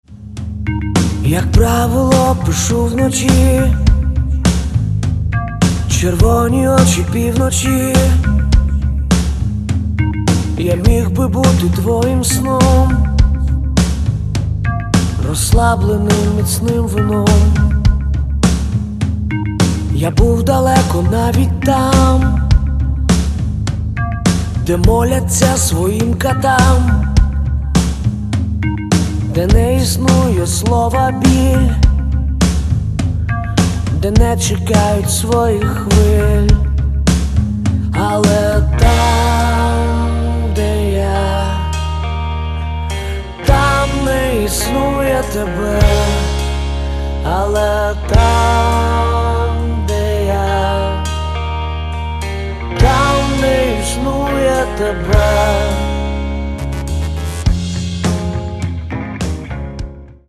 Rock & Alternative
A powerful album – at least, in terms of sounding.